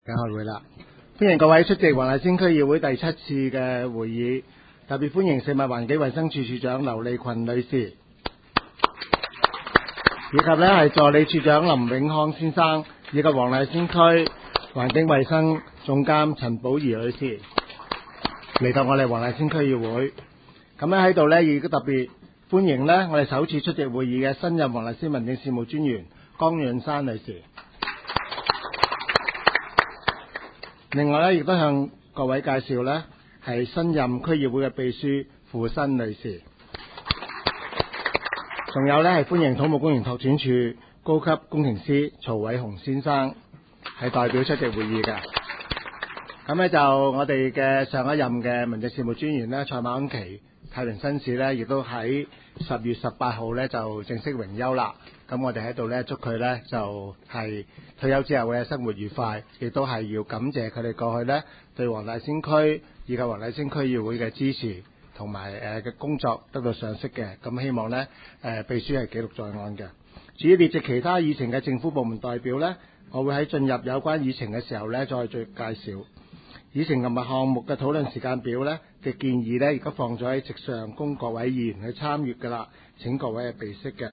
区议会大会的录音记录
黄大仙区议会会议室
开会致辞